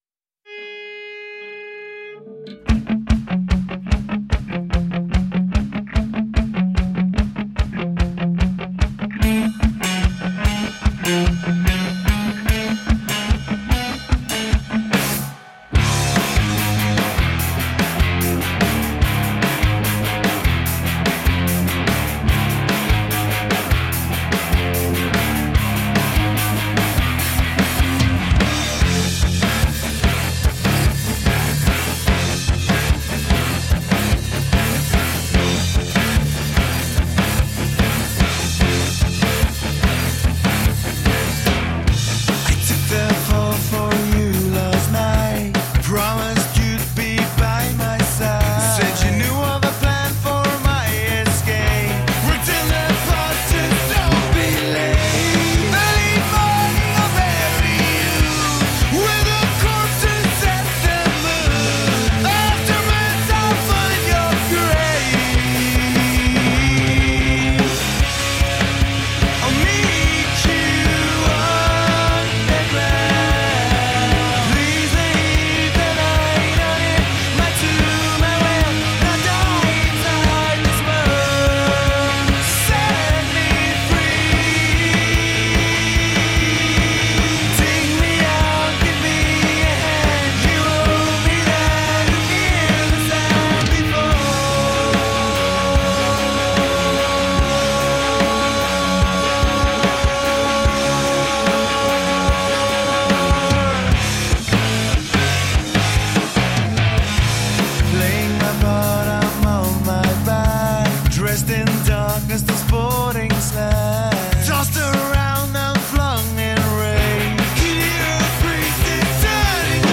Cunning & ferocious rock music.
Tagged as: Hard Rock, Metal